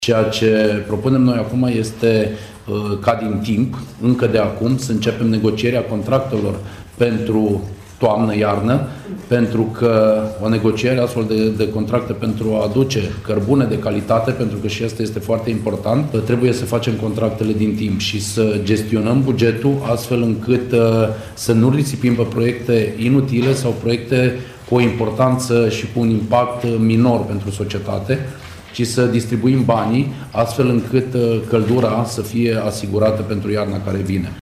La rândul său, viceprimarul Cosmin Tabără, președintele PNL Timișoara, spune că municipalitatea ar trebui să demareze negocierile pentru contractele de livrare a cărbunelui pe care le va încheia pentru iarna viitoare.